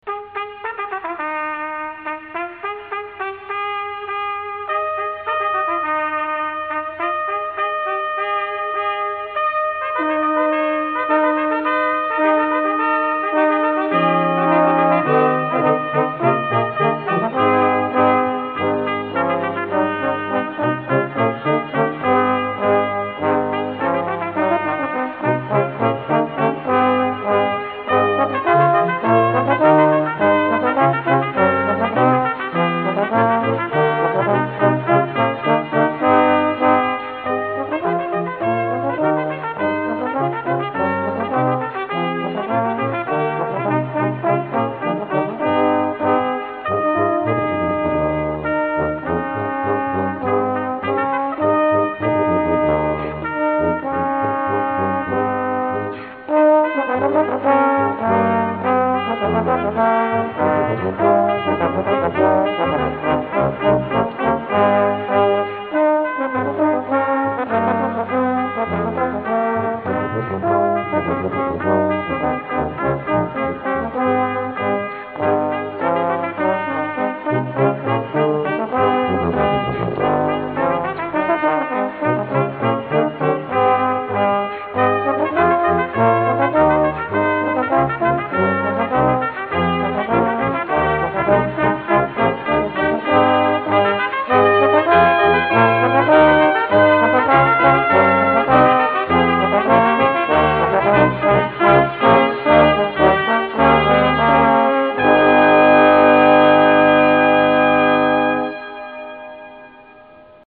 For Brass Quintet, Composed by Traditional.